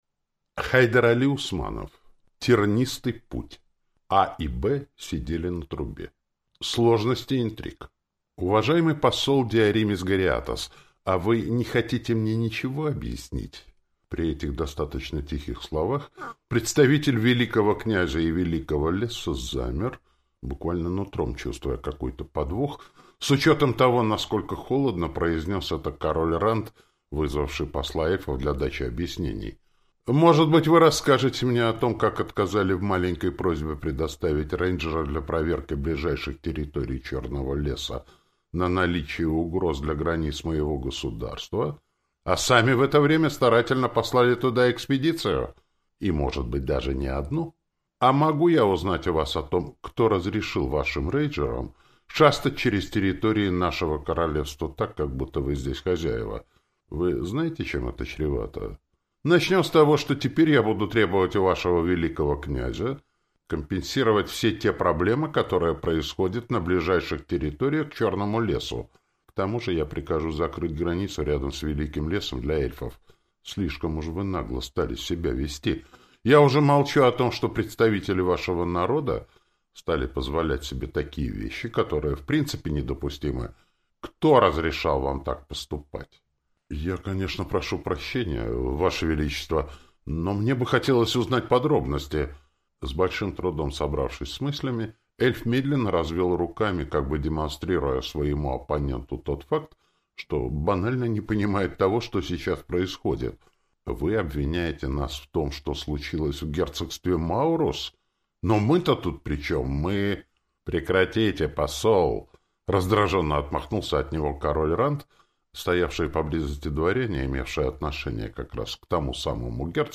Аудиокнига Тернистый путь. А и Б сидели на трубе | Библиотека аудиокниг